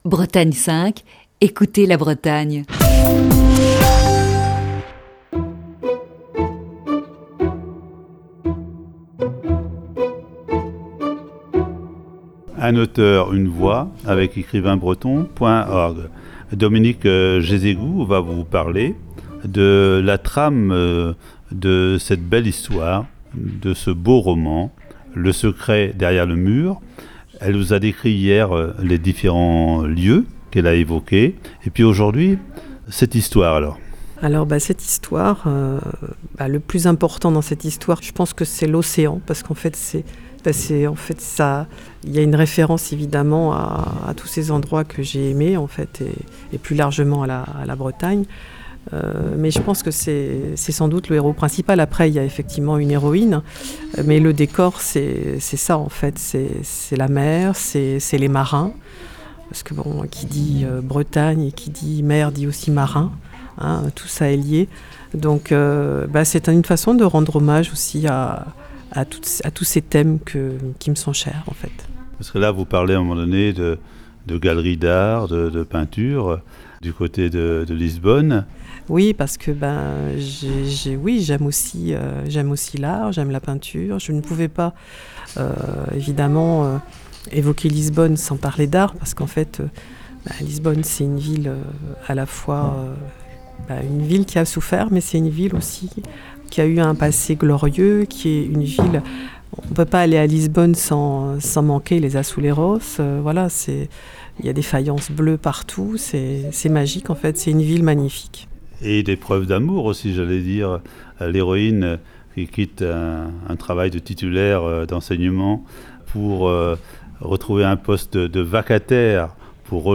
Voici ce vendredi la cinquième et dernière partie de cet entretien diffusé le 13 décembre 2019.